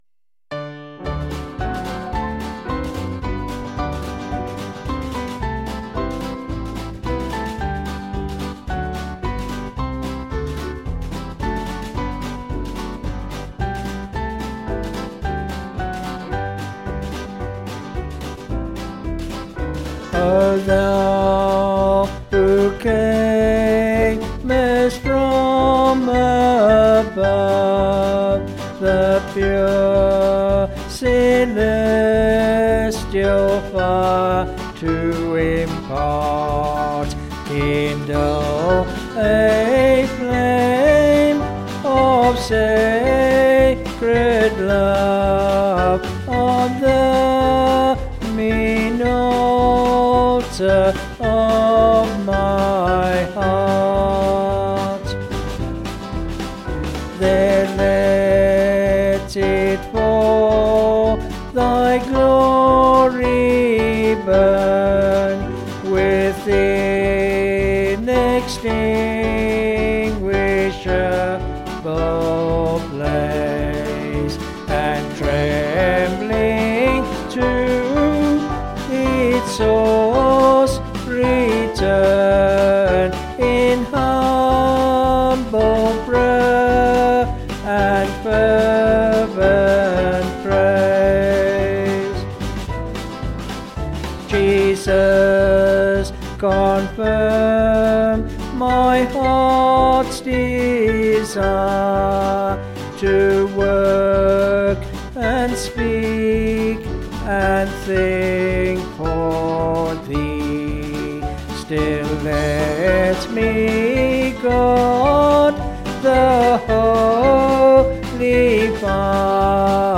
Vocals and Band   262.8kb Sung Lyrics